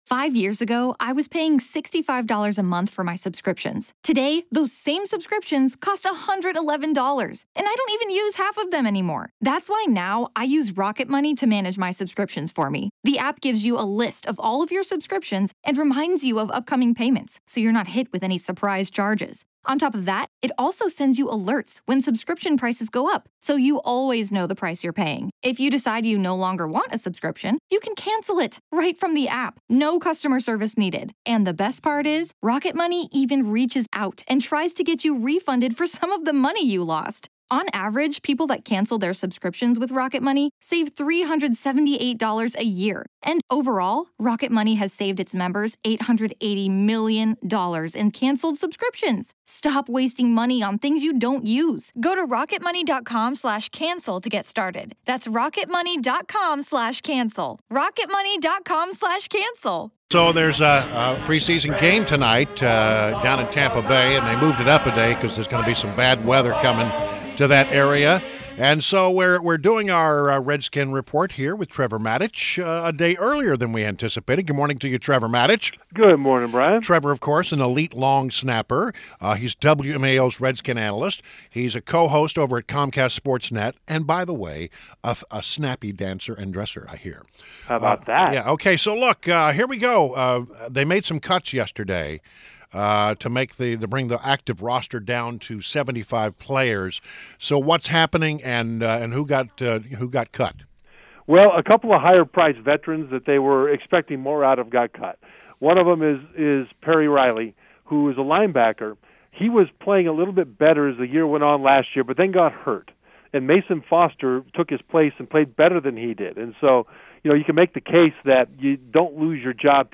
WMAL Interview - TREVOR MATICH - 08.31.16